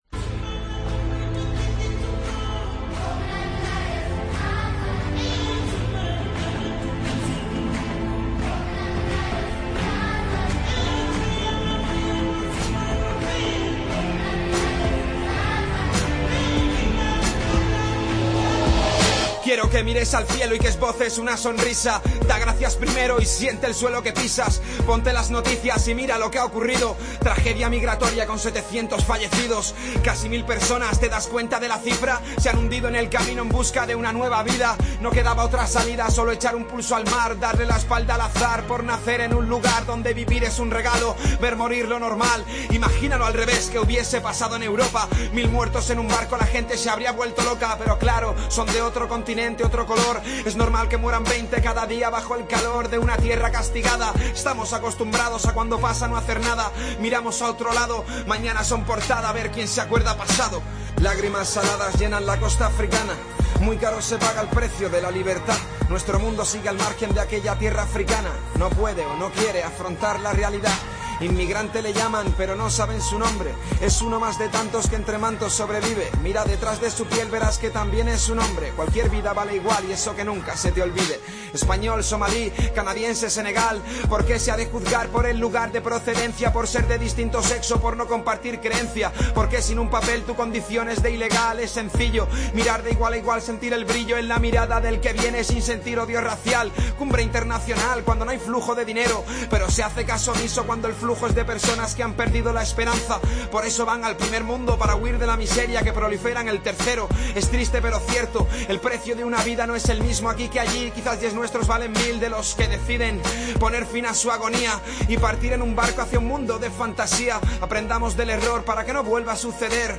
Tiempo de Juego a ritmo de rap